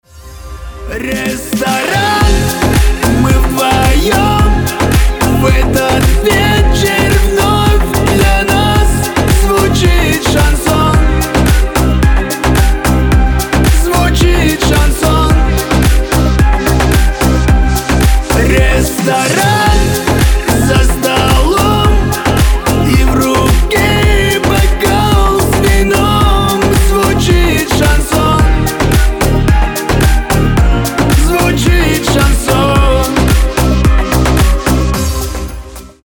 застольные